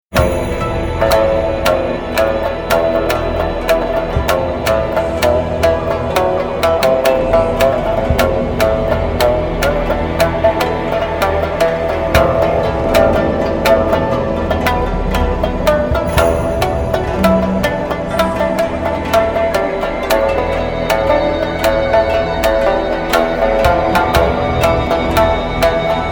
無限とも思えるほどの奥深い日本文化の神髄を洋楽器と共に表現し、日本人のDNAの中にある「和の心」を震わせる一枚。